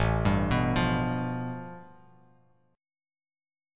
A7sus2#5 chord